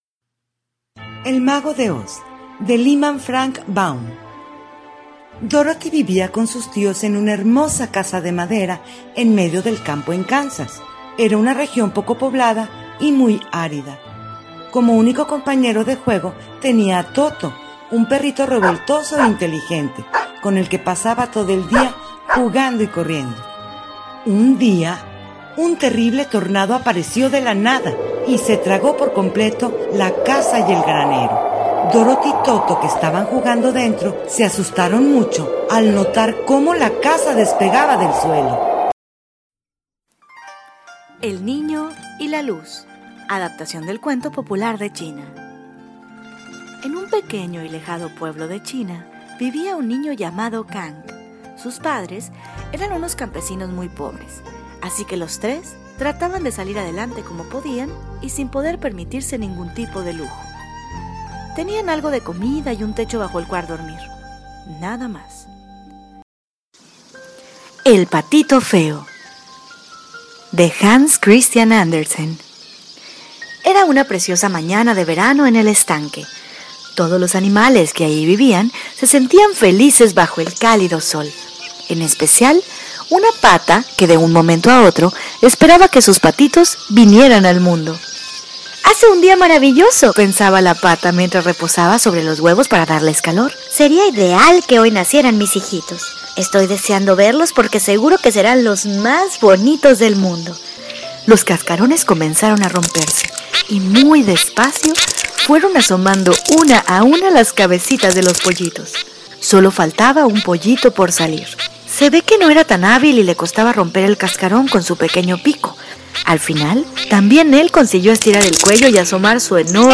Entretenidos Audiocuentos de los cuentos clásicos, con
efectos de sonido y diferentes voces.
demo-paquete5-audiocuentos.mp3